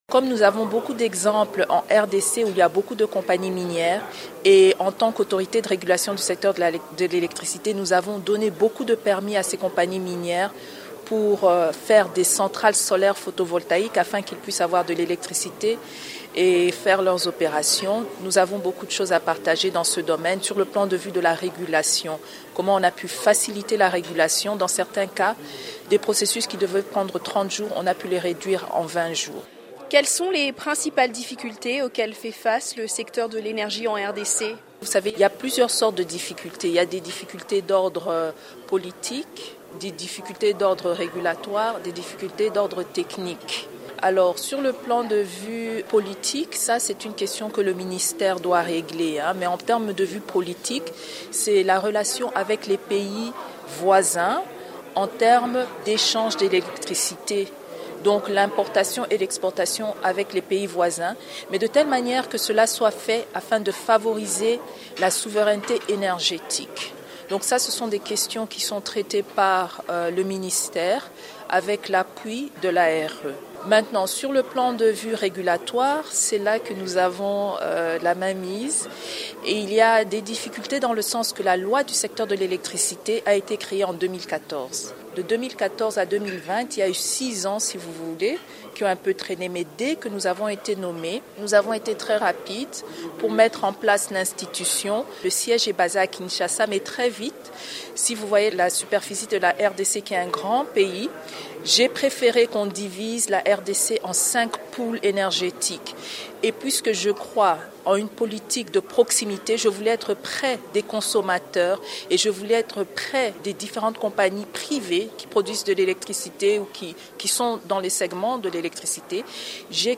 lors du Sommet Powering Africa à Washington.